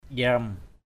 /ʄrʌm/ 1.